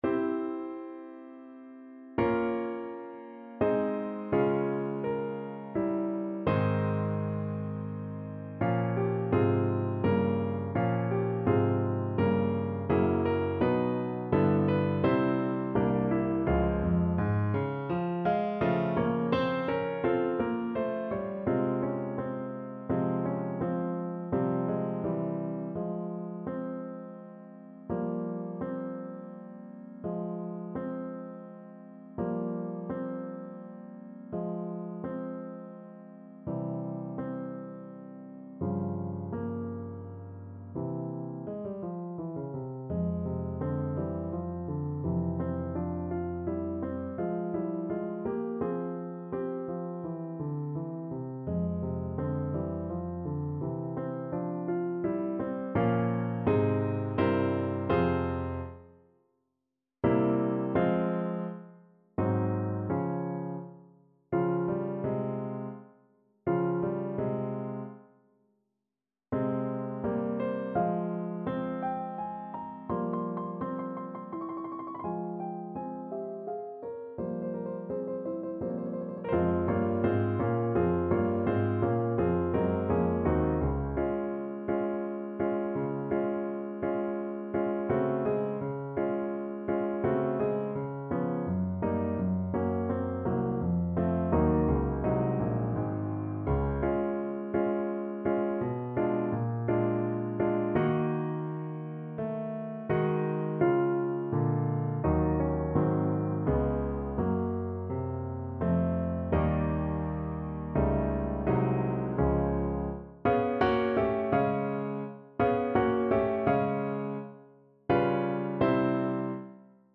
3/4 (View more 3/4 Music)
Andante =84
Classical (View more Classical Clarinet Music)